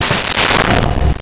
Door Metal Slam 01
Door Metal Slam 01.wav